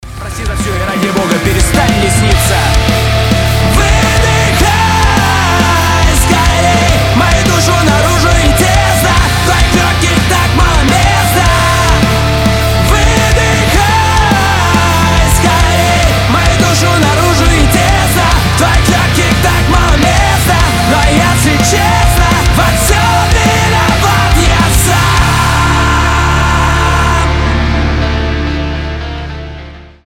• Качество: 320, Stereo
громкие
Cover
Alternative Metal
nu metal
Mashup
Рэп-рок